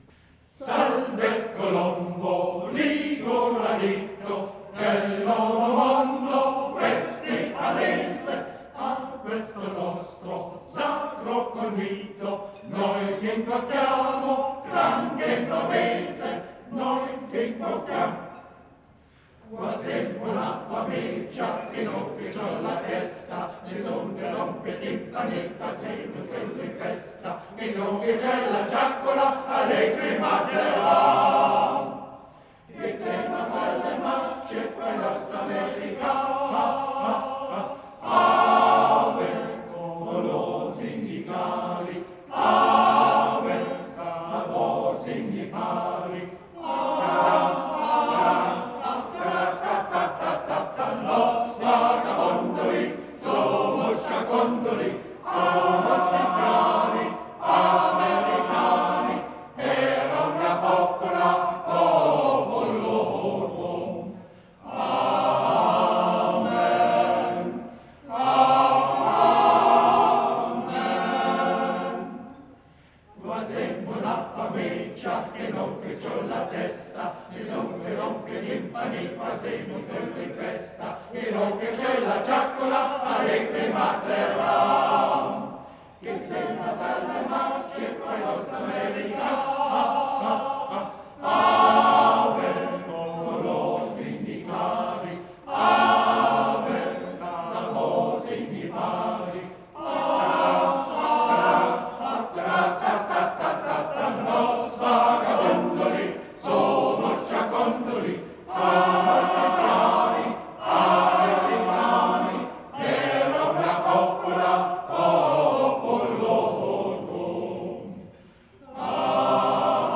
Sängerkranz Alfdorf – Salve o Colombo (21.01.2017 Jahresfeier)